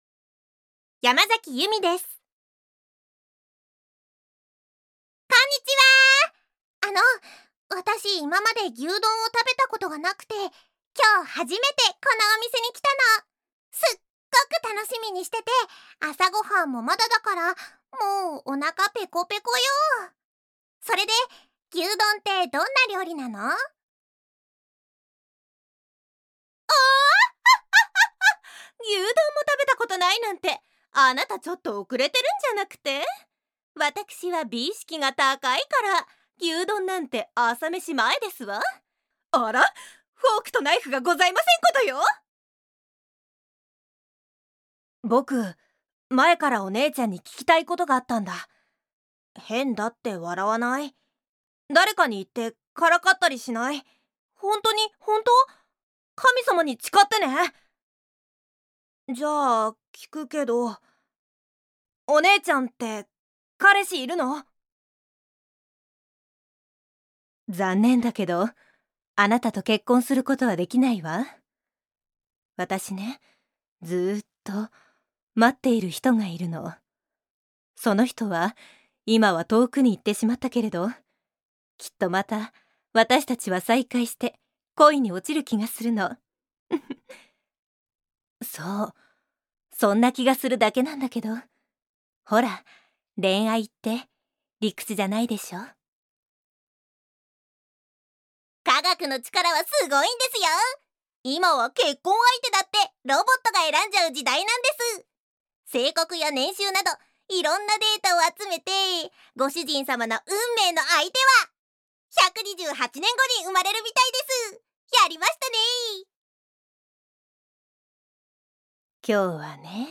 voice actor
サンプルボイス